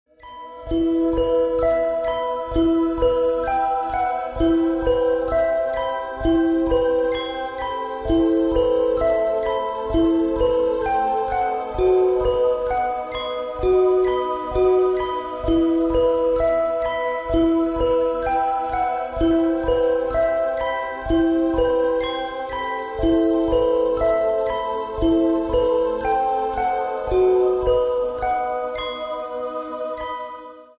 • Качество: 128, Stereo
женский голос
Electronic
спокойные
без слов
красивая мелодия
Музыкальная шкатулка